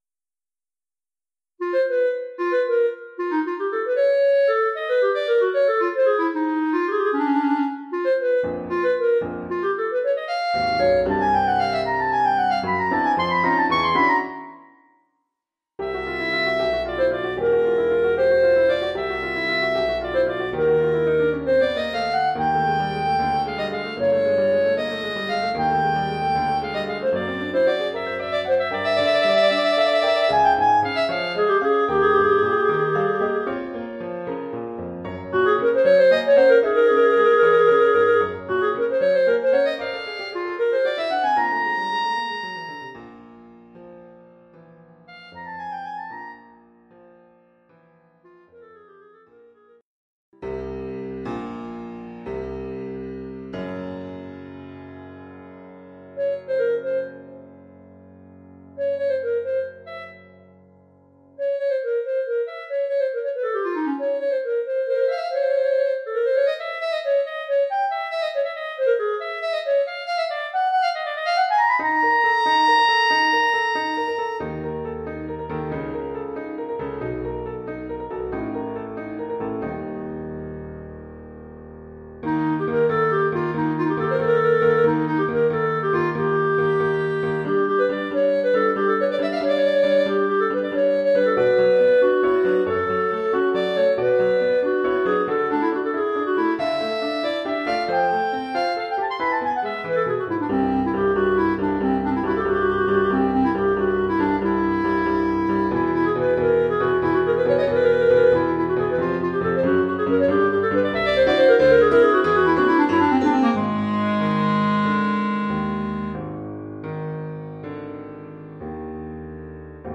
Oeuvre pour clarinette et piano.